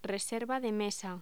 Locución: Reserva de mesa
Sonidos: Voz humana
Sonidos: Hostelería